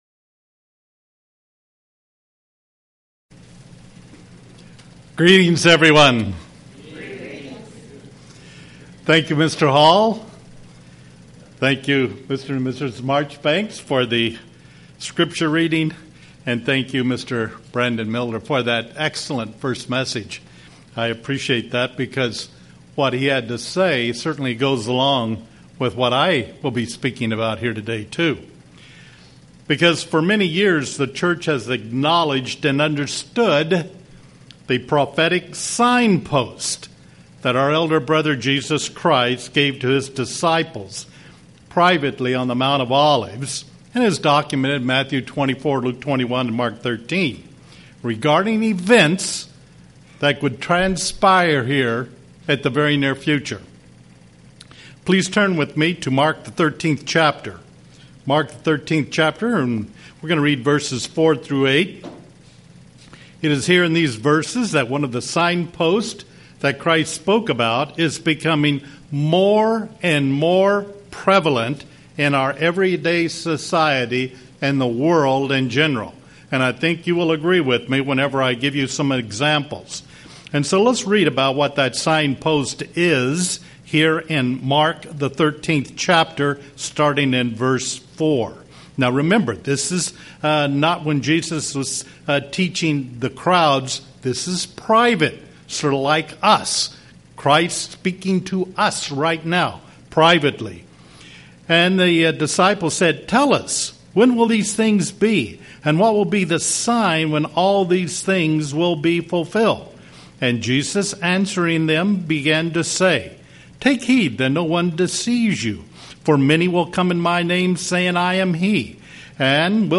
Proverbs:18:13 Proverbs: 15:1 UCG Sermon Studying the bible?